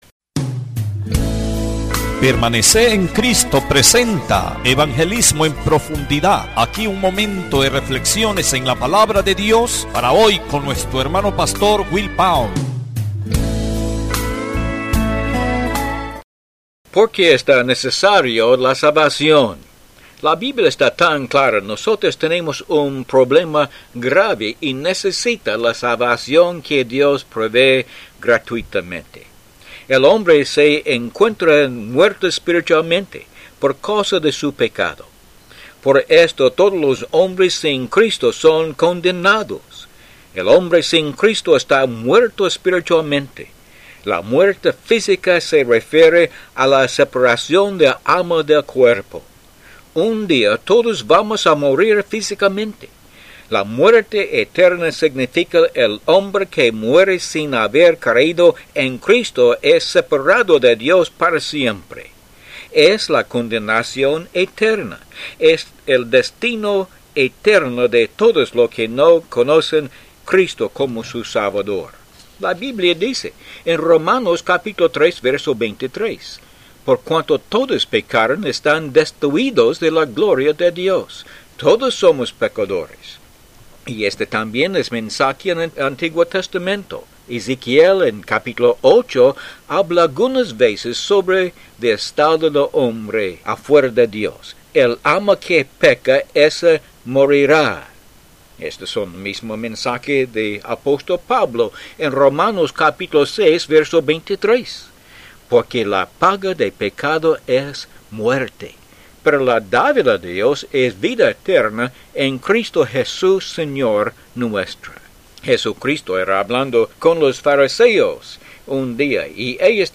Disfrute estos programas de cinco minutos Cristianos de radio.